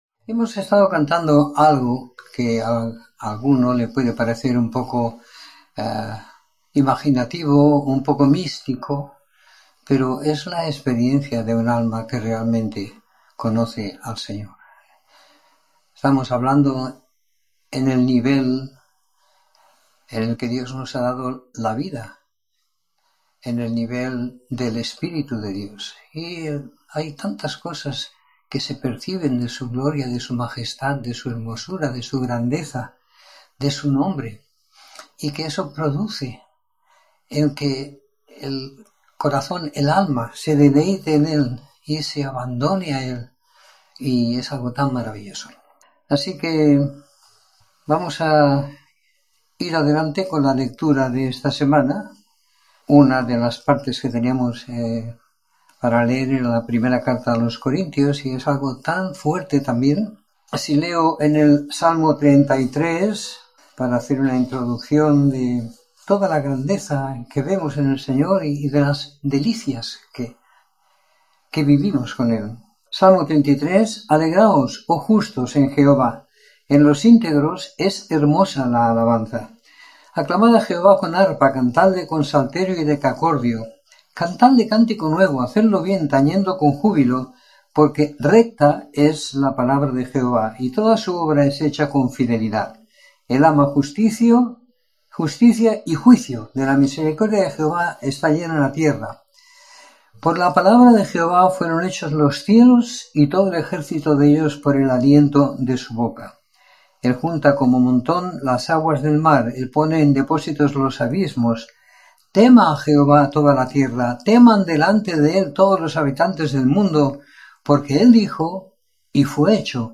Comentario en 1ª Corintios - 19 de Junio de 2020
Comentario en la epístola a los Corintios siguiendo la lectura programada para cada semana del año que tenemos en la congregación en Sant Pere de Ribes.